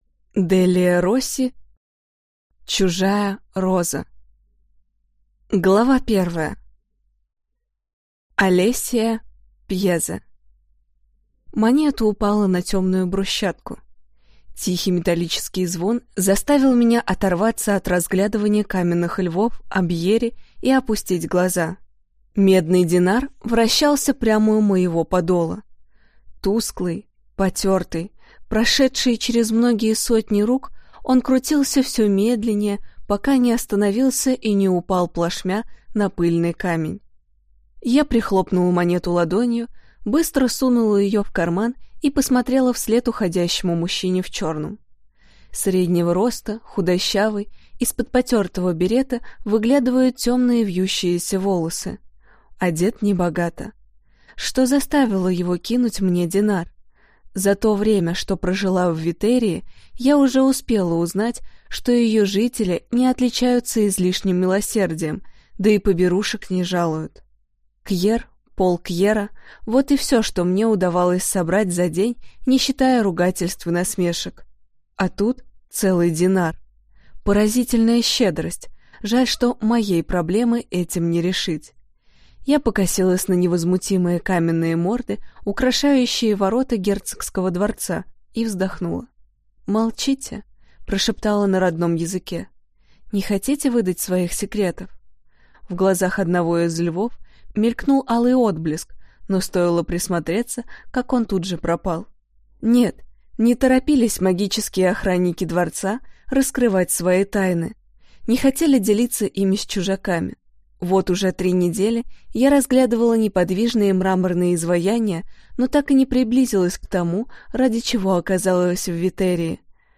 Аудиокнига Чужая роза | Библиотека аудиокниг